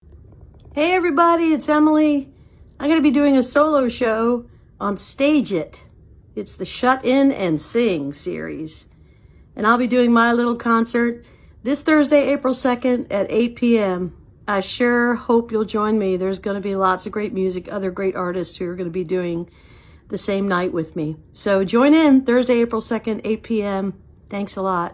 (captured from the live video stream)
01. promo (0:24)